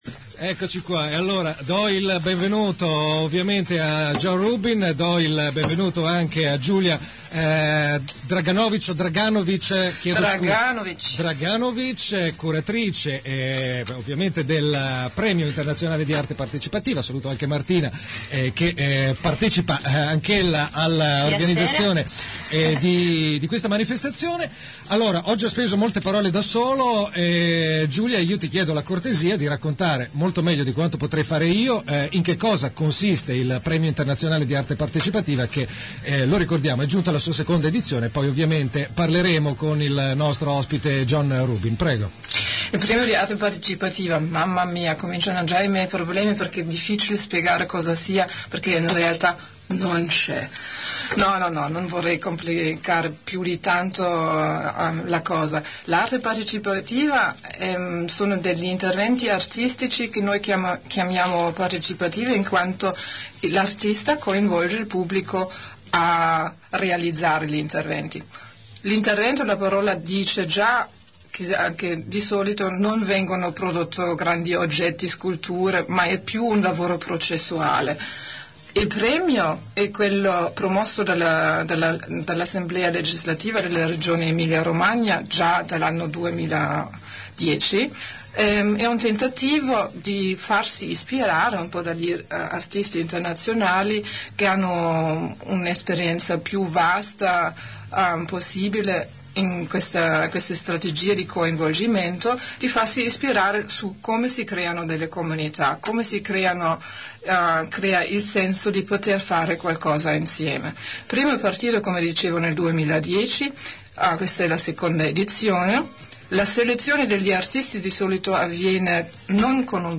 è stato nei nostri studi.